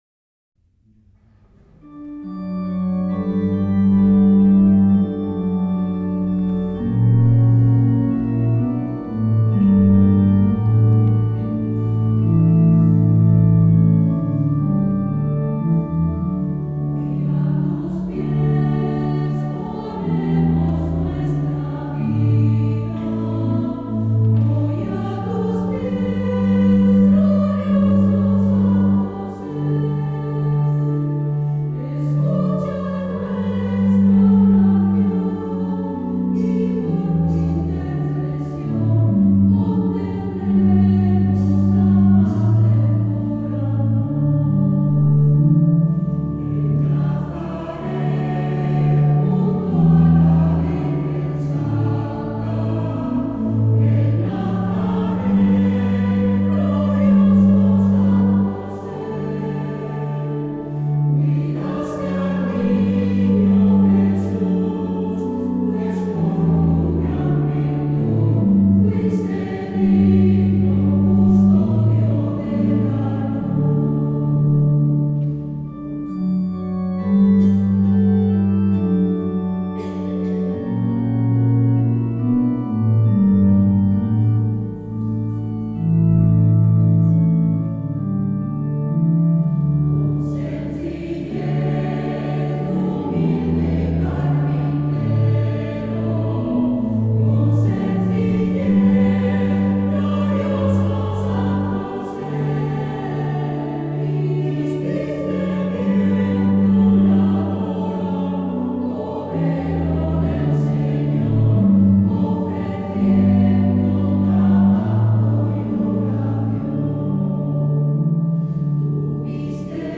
Himno-a-San-Jose-Coro-Santa-Maria_2025.mp3